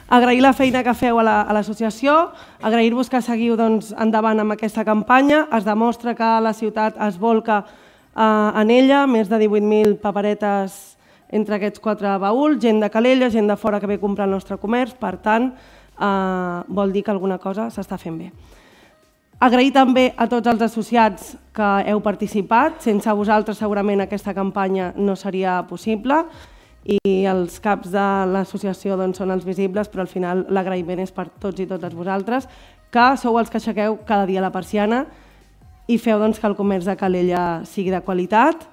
Es van donar a conèixer ahir en el sorteig celebrat a l’Auditori Josep-Maria Terricabras, que es va poder seguir en directe per Ràdio Calella TV.
En la mateixa línia es va expressar la tinenta d’Alcaldia de Promoció Econòmica, Cindy Rando, que va acompanyar la gala, igual que altres membres de la corporació municipal, tant del govern com de l’oposició.